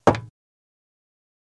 Singles|Metal
Footstep, Loafer On Metal Mono